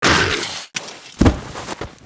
带人声的死亡倒地4zth070518.wav
通用动作/01人物/02普通动作类/带人声的死亡倒地4zth070518.wav
• 声道 單聲道 (1ch)